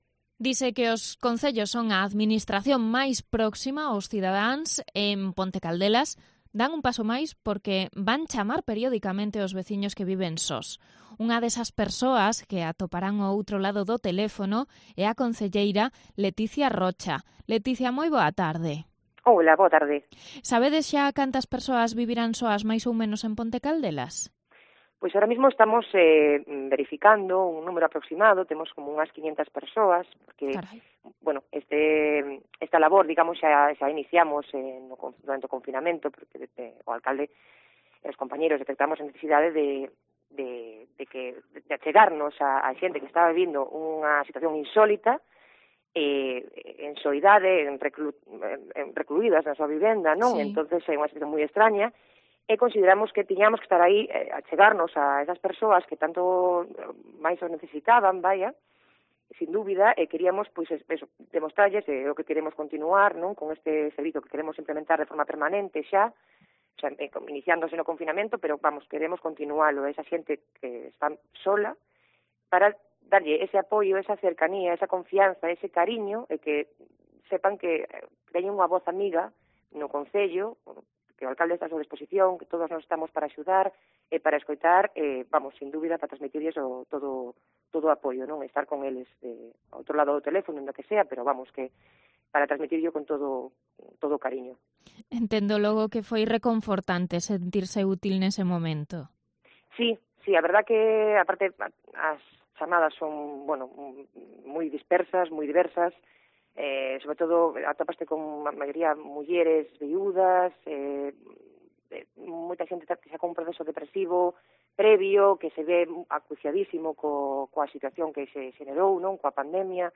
Entrevista a la concejala Leticia Rocha sobre la prevención de la soledad en Ponte Caldelas